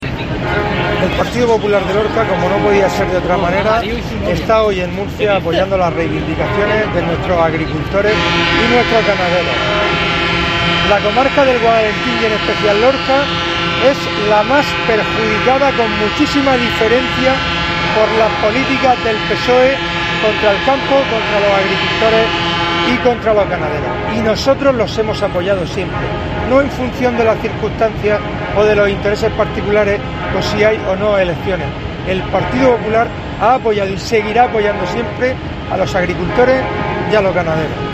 Fulgencio Gil, portavoz del PP en Lorca